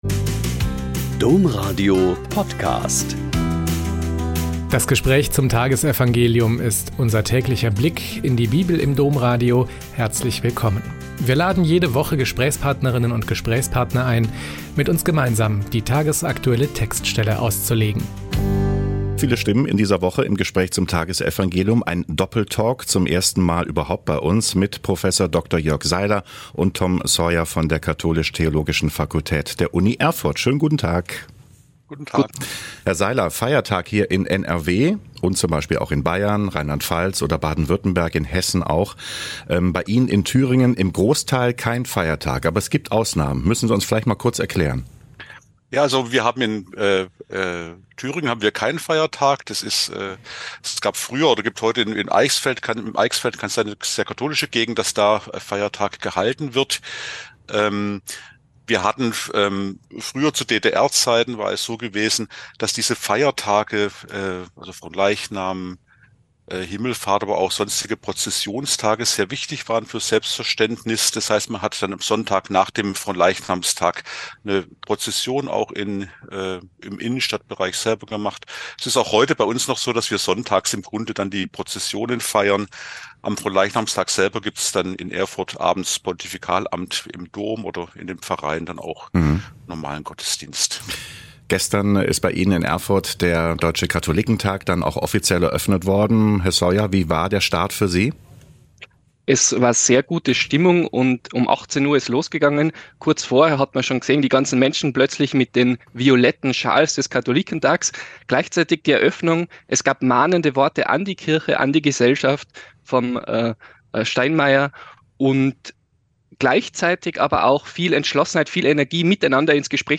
Mk 14,12-16,22-26 - Gespräch